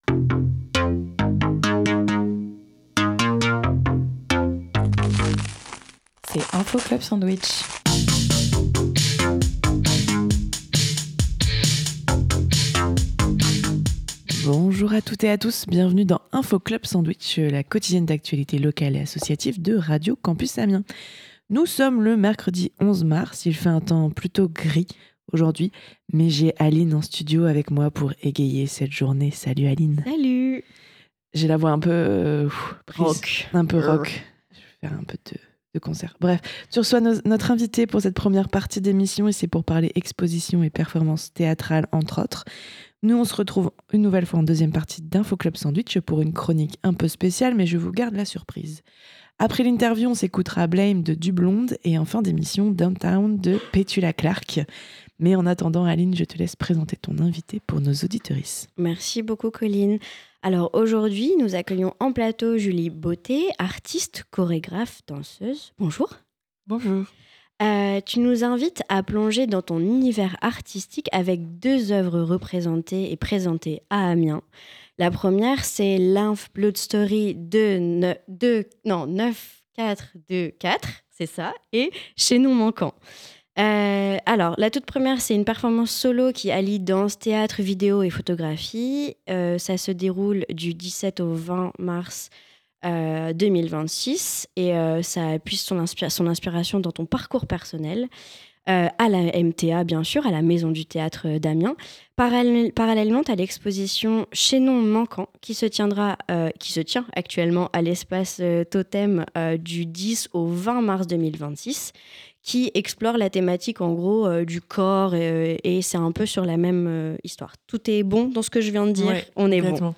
dans les studios de Radio Campus Amiens